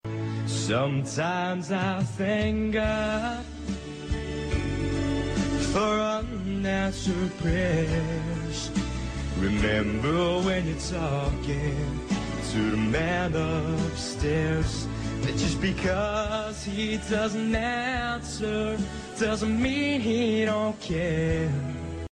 Country Ringtones